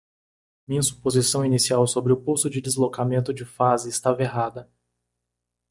/su.po.ziˈsɐ̃w̃/